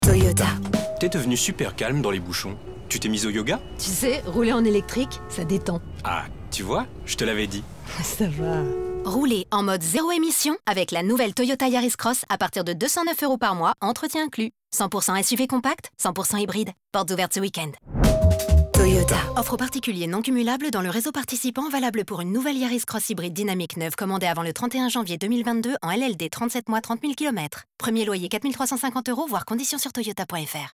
Démo voix 2